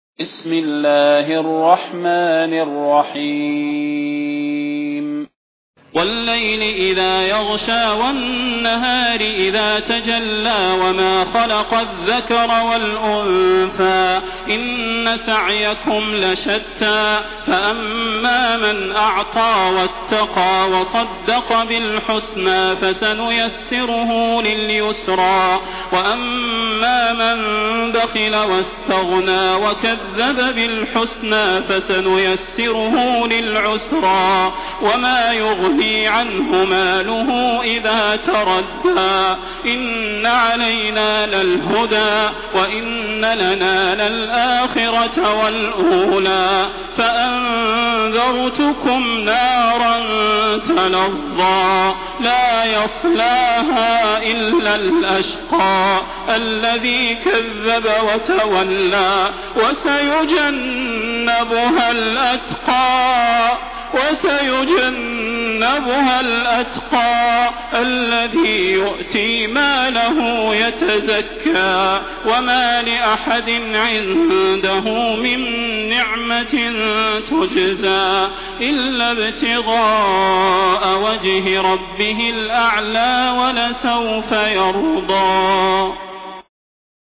المكان: المسجد النبوي الشيخ: فضيلة الشيخ د. صلاح بن محمد البدير فضيلة الشيخ د. صلاح بن محمد البدير الليل